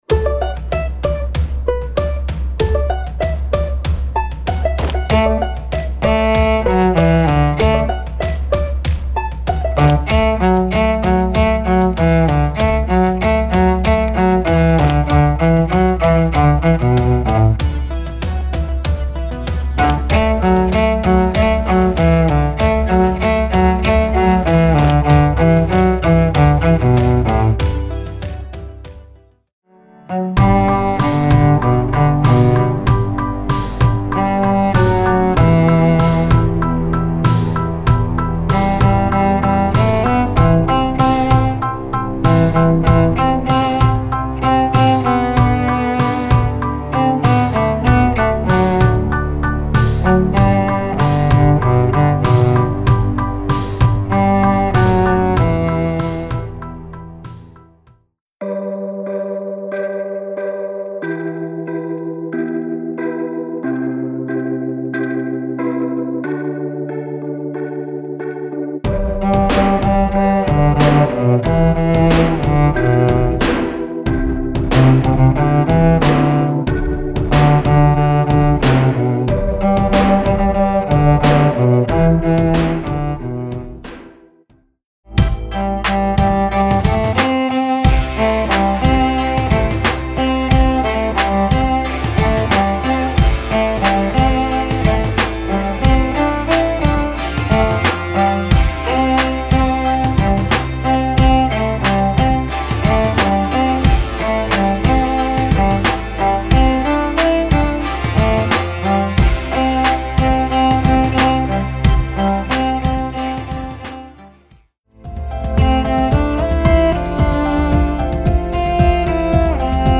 Voicing: Cello and Online Audio